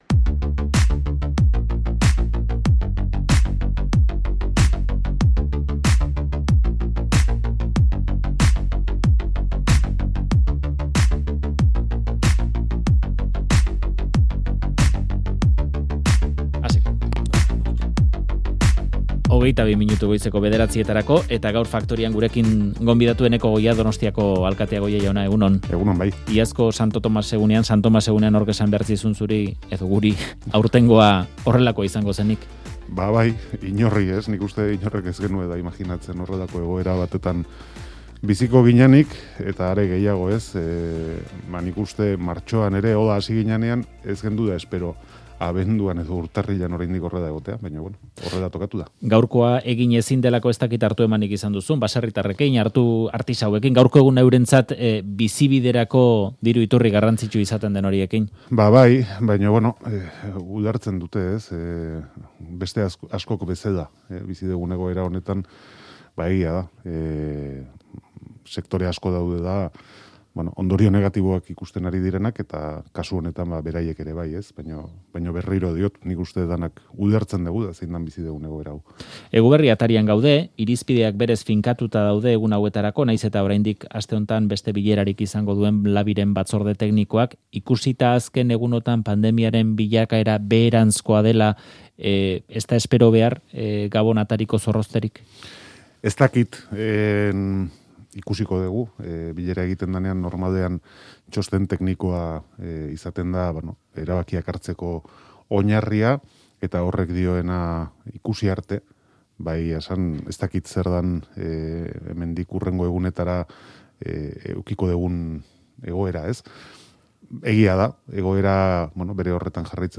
Audioa: Eneko Goia, Donostiako alkatea, Faktoria, Euskadi Irratian.